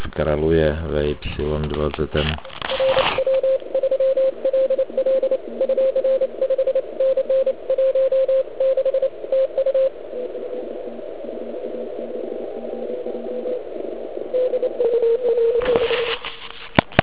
Band je klasicky "panelákově zaprskán asi na S9.